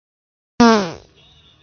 真实的放屁 " 放屁18
描述：真屁
Tag: 现实 放屁 真正